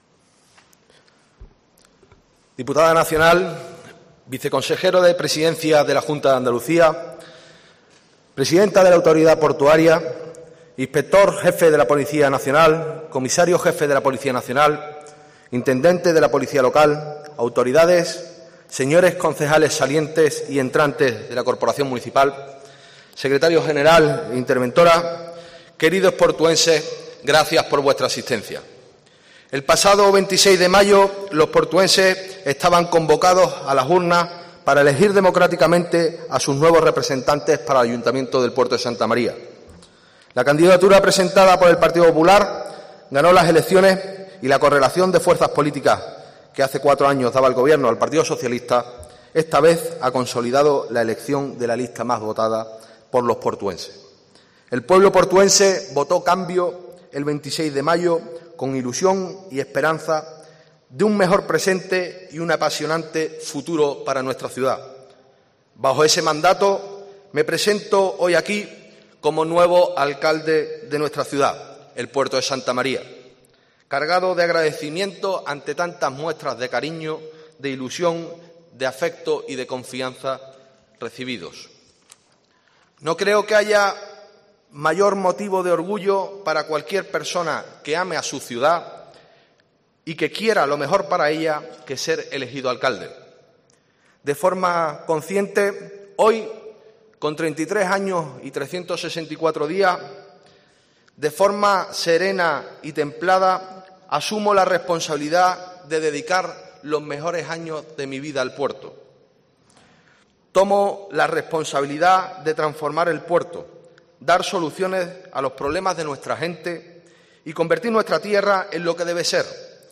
Discurso de investidura de Germán Beardo, alcalde de El Puerto de Santa María